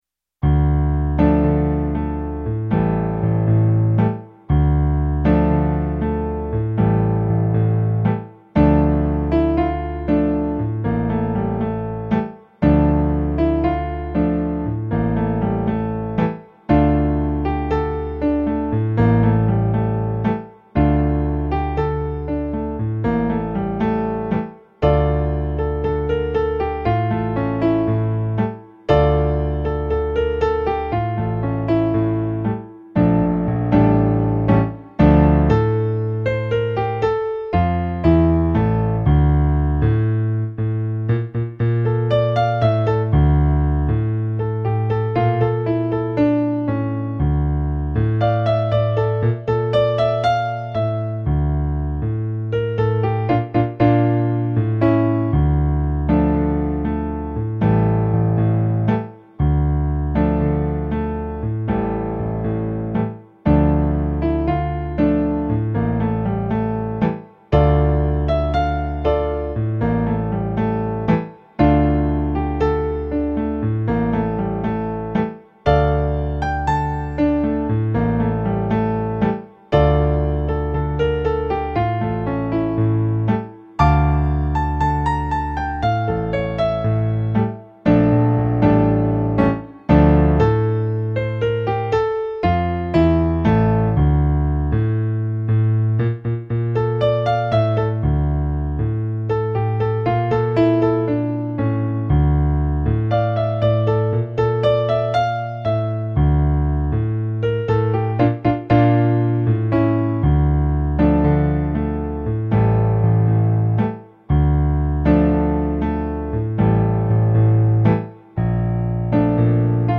contains eight piano solo arrangements.
Latin remix